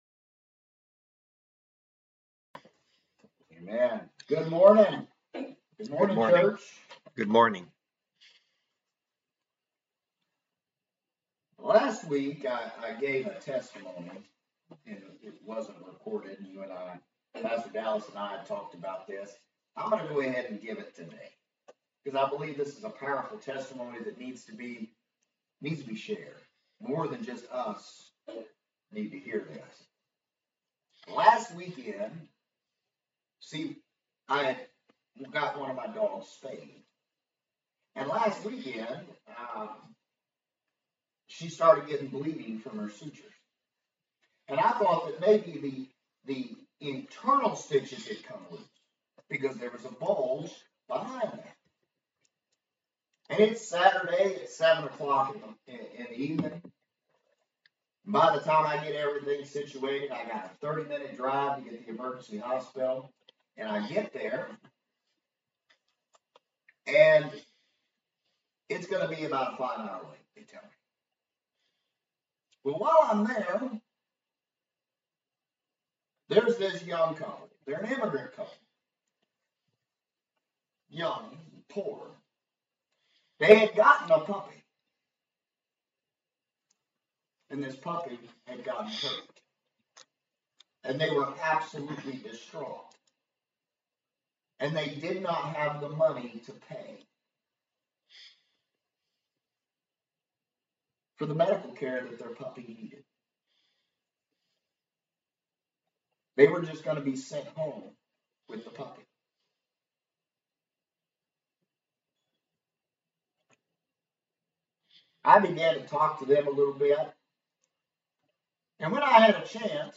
Passage: James 2 Service Type: Sunday Service